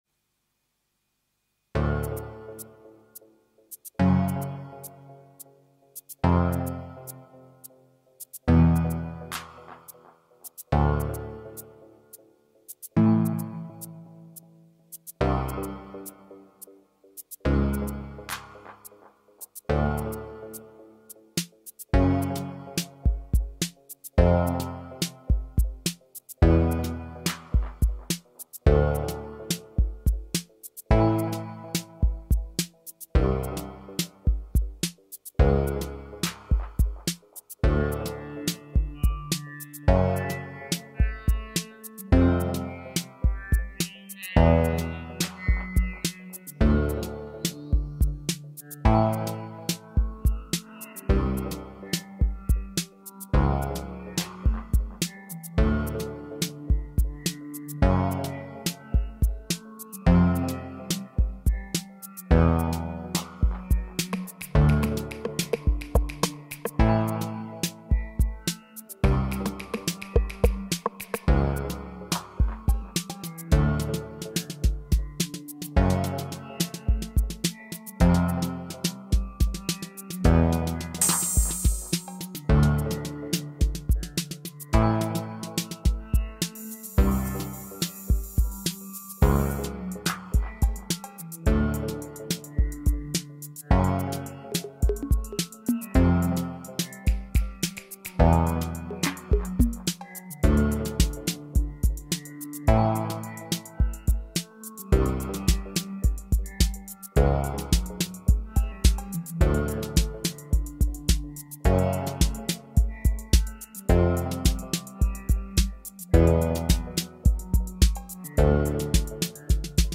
I wanted a spacious, slightly creepy feel with some groove.
Sequenced on a Squarp Pyramid.
I looped in my hand-built delay and phaser pedals.